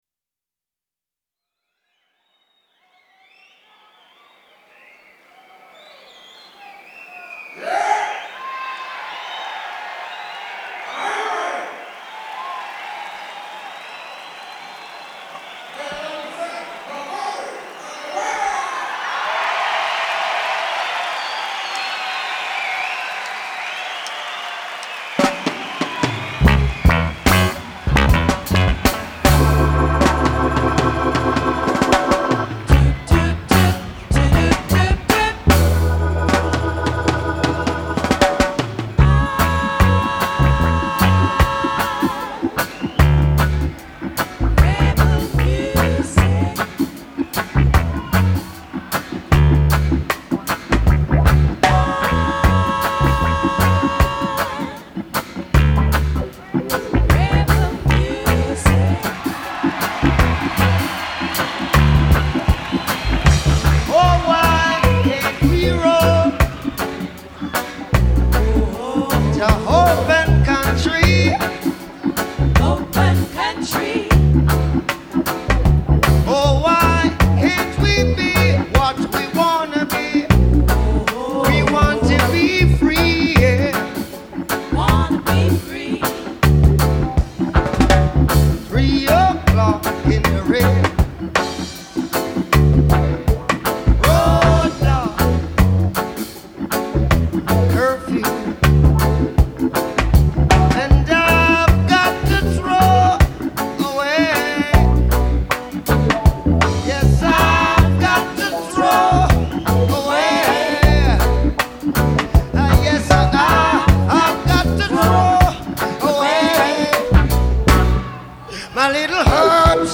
Genre : Reggae, Musiques du monde
Live At The Rainbow Theatre, London